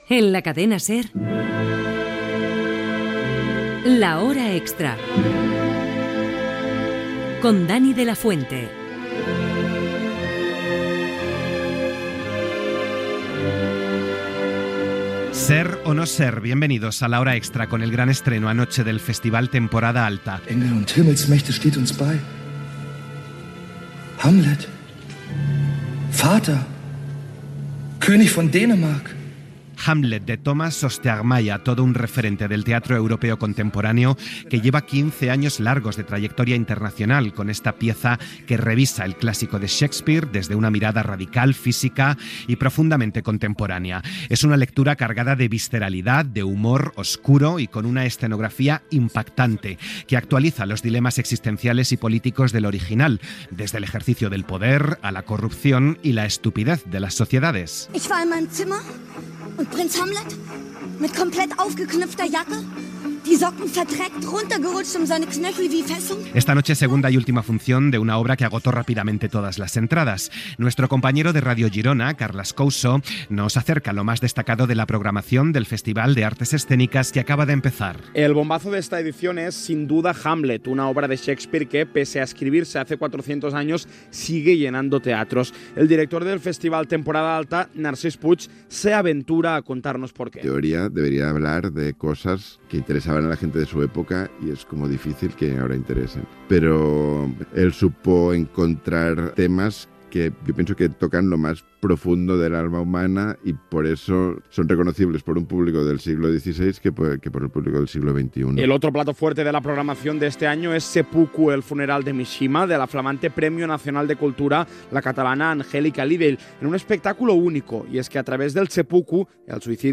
Indicatiu del programa, benvinguda, informació sobre el festival "Temporada alta" i l'obra "Hamlet".